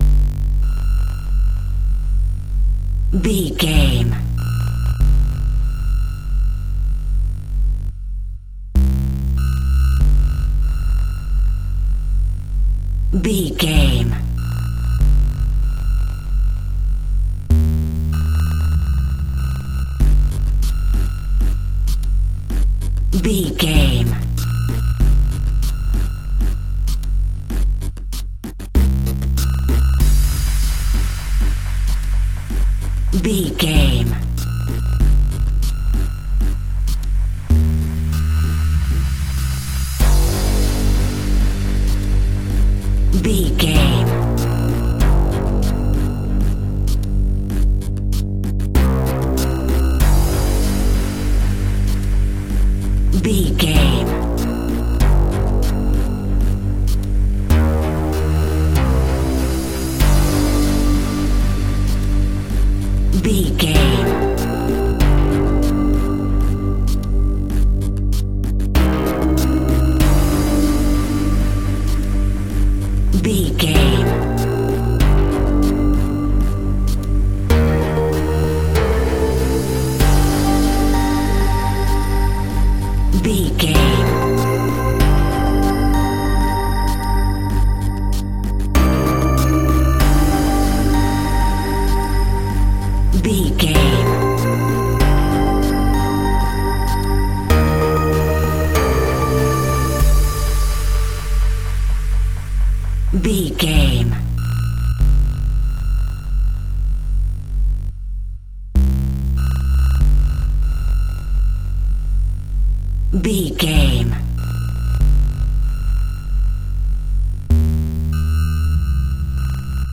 Aeolian/Minor
scary
tension
ominous
dark
eerie
synthesiser
mysterious
ticking
electronic music